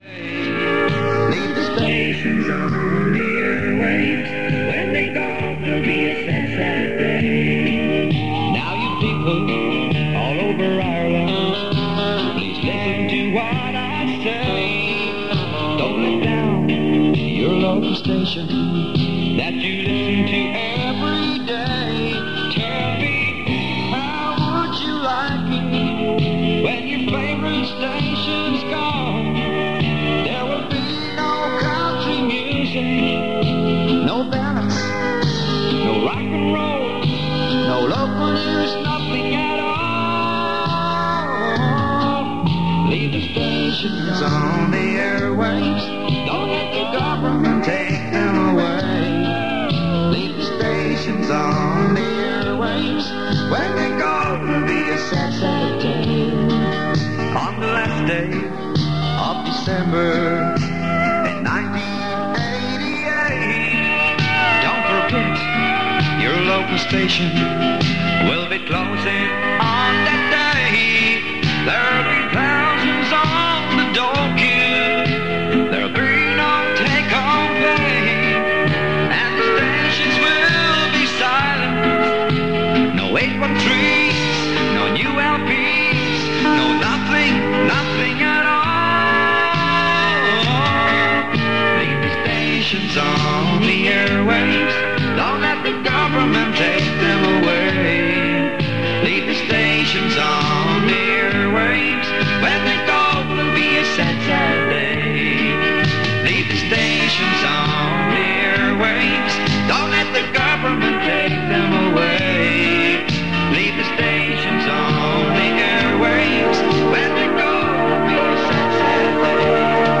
This recording was made on a Friday night a fortnight before Radio West closed down at the end of 1988. It consists of continuous music interspersed with station idents and jingles. There are occasional interruptions suggesting that this may be a pre-recorded tape, although it would be surprising that a live DJ would not be on air at that time of the day.